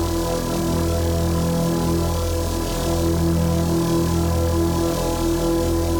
Index of /musicradar/dystopian-drone-samples/Non Tempo Loops
DD_LoopDrone1-E.wav